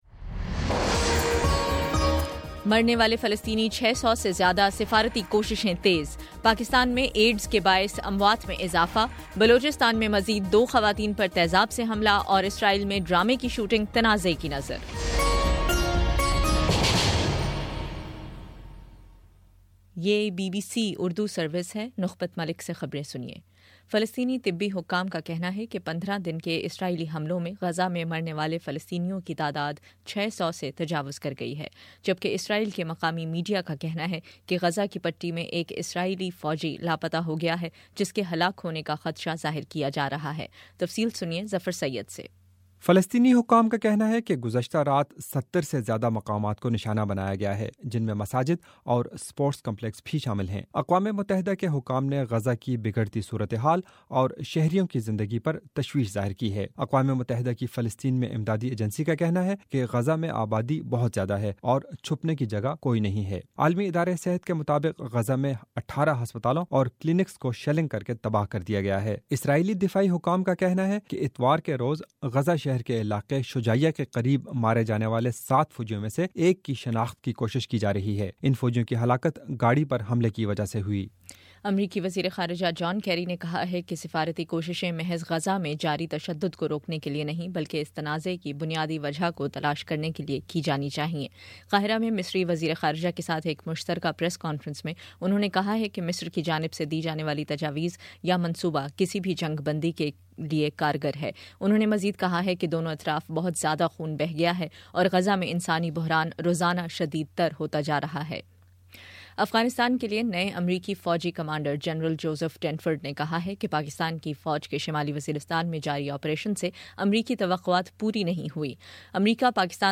بائیس جولائی: شام 7 بجے کا نیوز بُلیٹن
دس منٹ کا نیوز بُلیٹن روزانہ پاکستانی وقت کے مطابق صبح 9 بجے، شام 6 بجے اور پھر 7 بجے۔